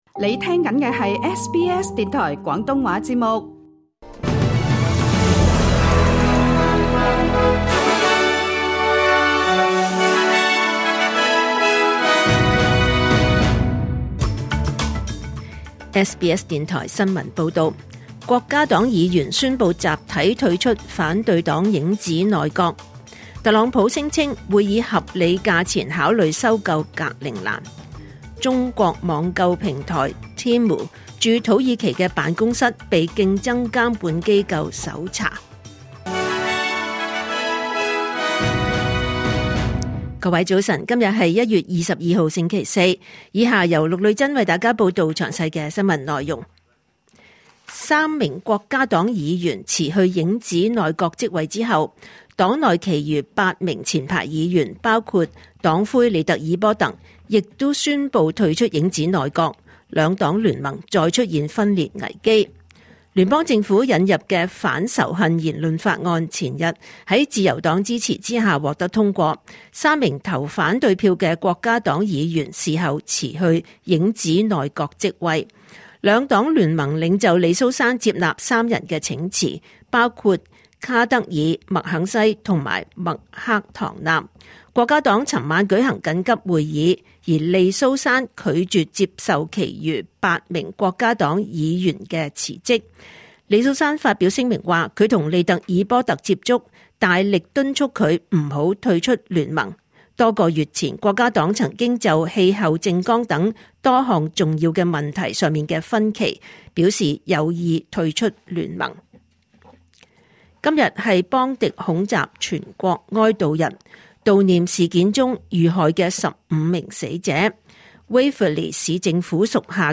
2026年1月22日SBS廣東話節目九點半新聞報道。